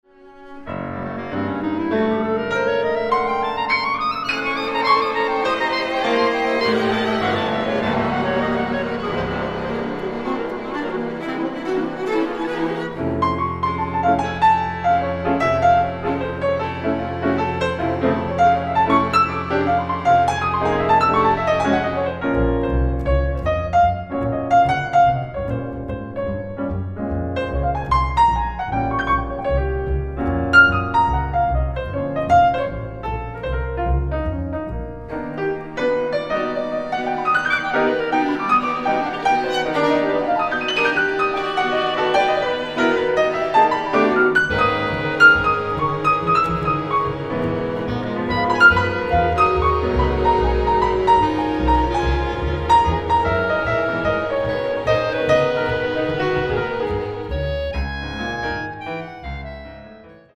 Jazz, World & Light